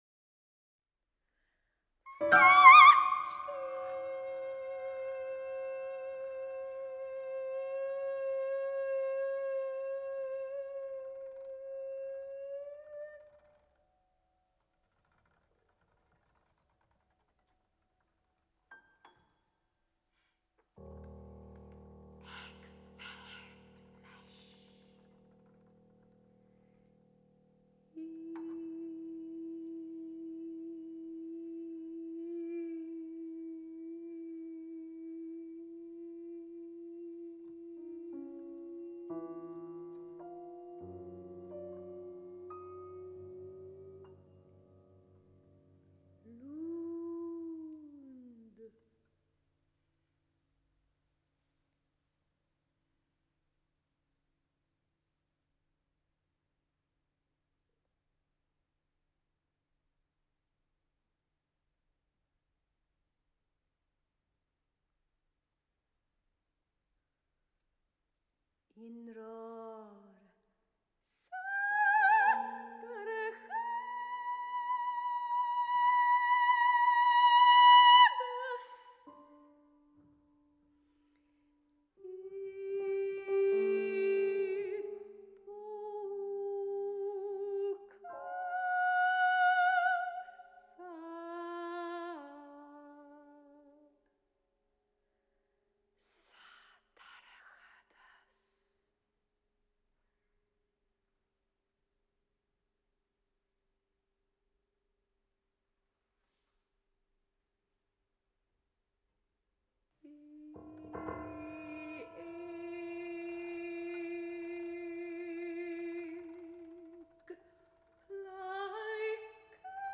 Taifead amhráin / Song recording